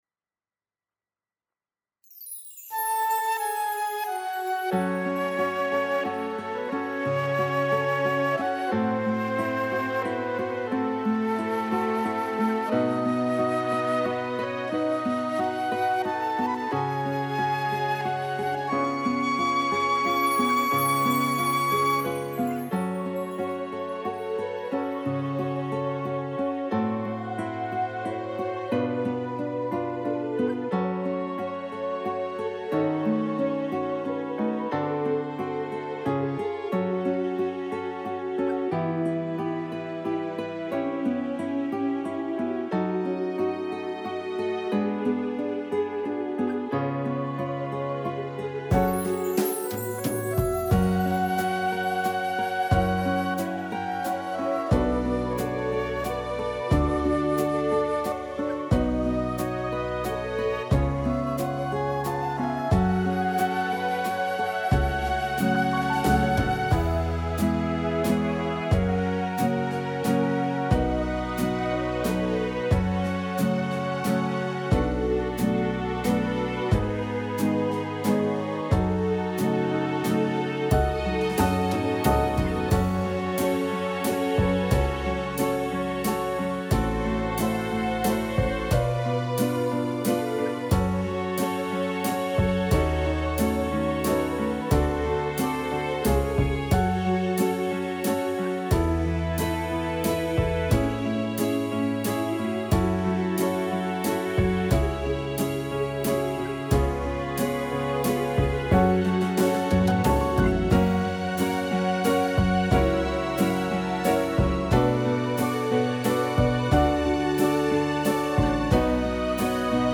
•   Beat  02.
F#m 3:56